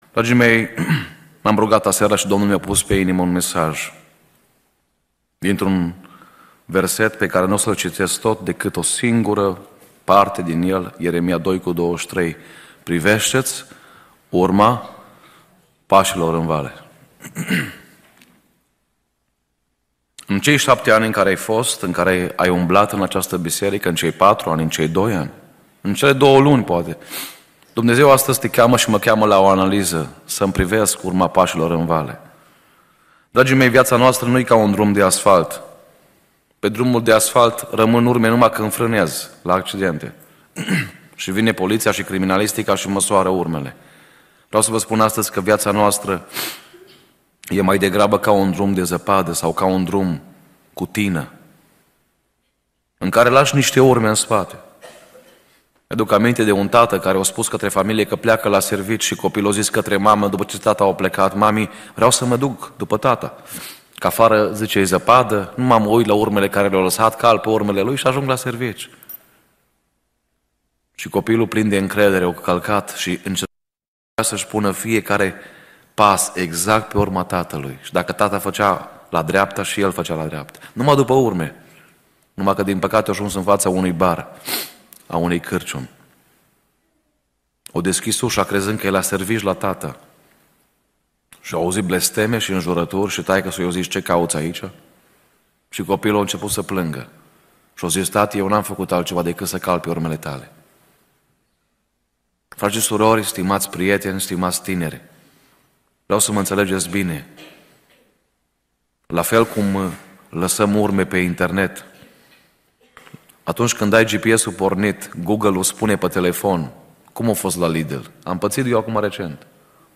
Cuprinde o selectie de predici audio si text care te ajuta sa intelegi de unde vii, cine esti si ce vrea Dumnezeu de la tine.